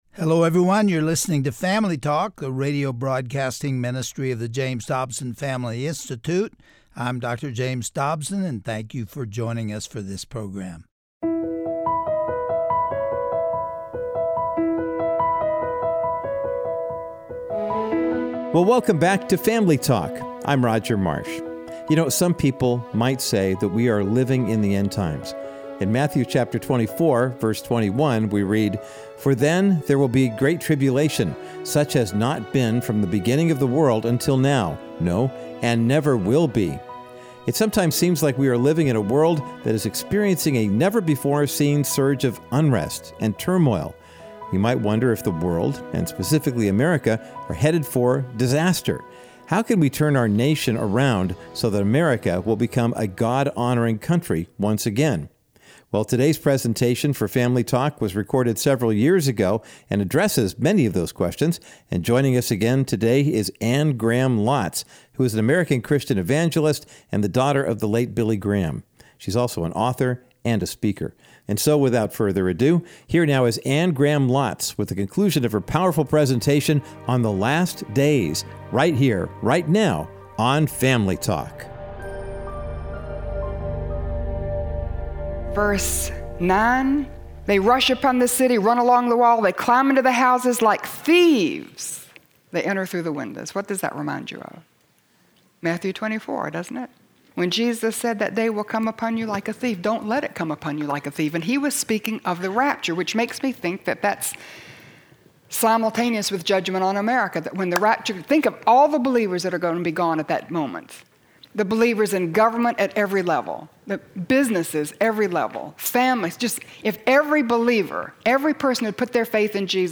On today’s edition of Family Talk, we will listen as Anne Graham Lotz concludes her stirring message of hope, reminding us that no one sits higher than Jesus on His throne.